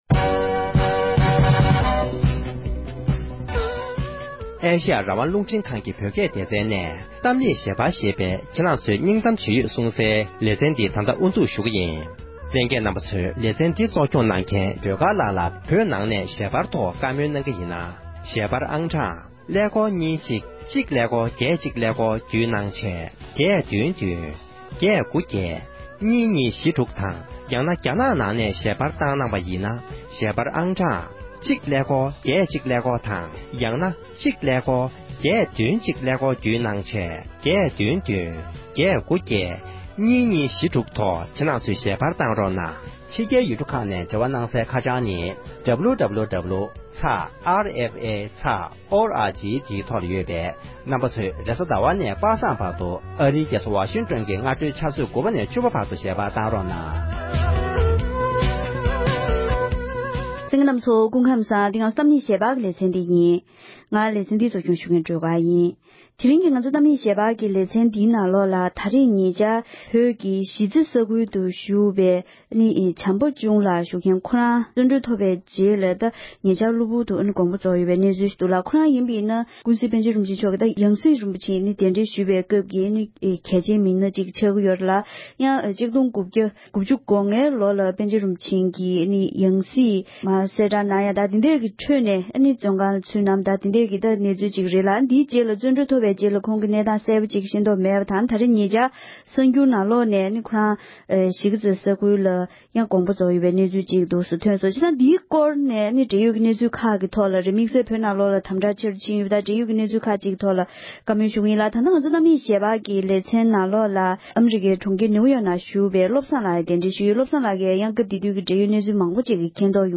བགྲོ་གླེང་ཞུས་པ་ཞིག་གསན་རོགས།